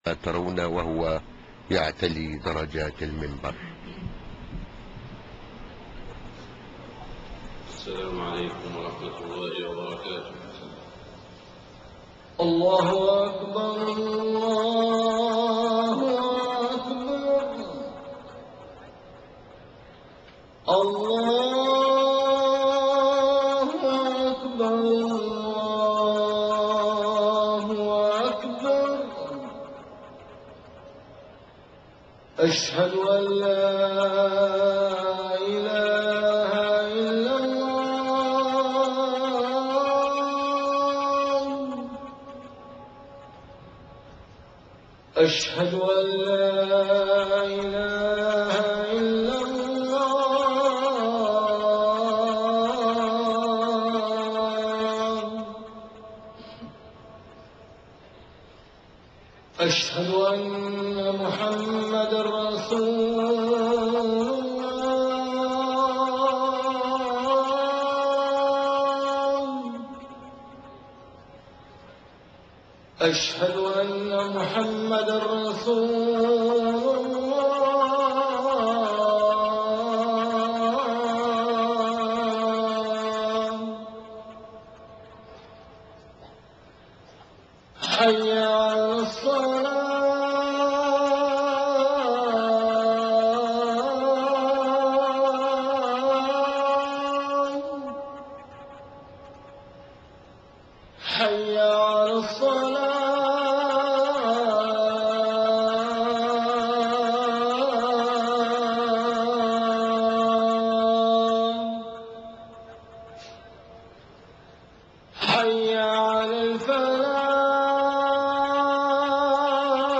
خطبة الجمعة 12 محرم 1430هـ > خطب الحرم المكي عام 1430 🕋 > خطب الحرم المكي 🕋 > المزيد - تلاوات الحرمين